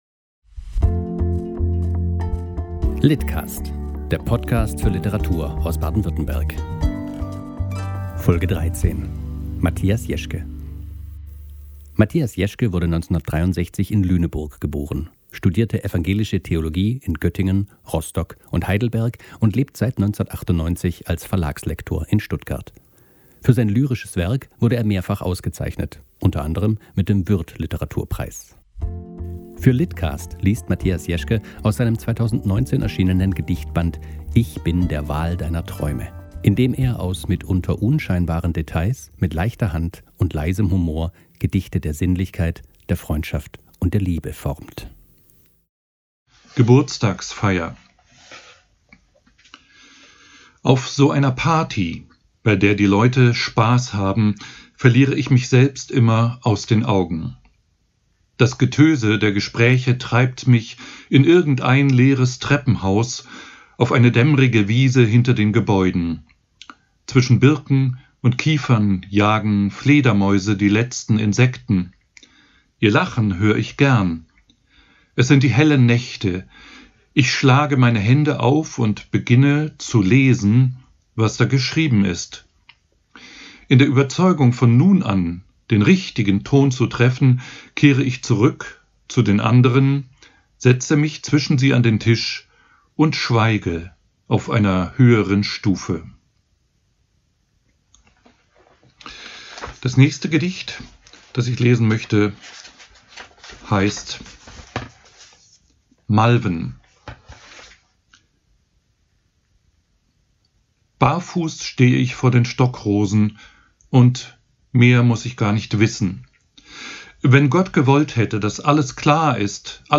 liest aus "Ich bin der Wal deiner Träume"